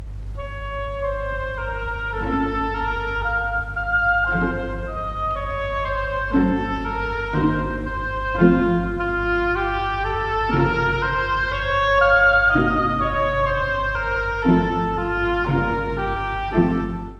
↑古い録音のため聴きづらいかもしれません！（以下同様）
ロシア民謡を引用した、ひじょうにメランコリックな曲です。